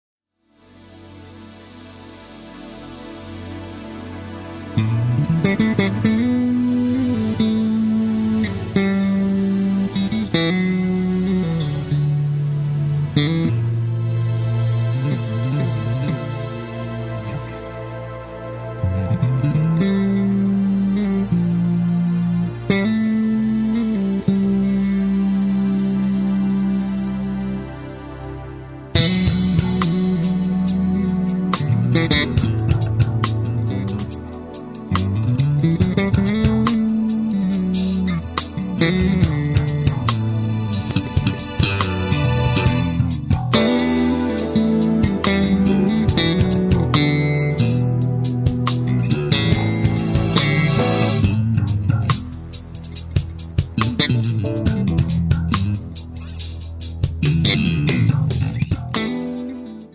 chitarra elettrica
basso
batteria
sassofoni
tastiere
tromba.
ritmi funk contagiosi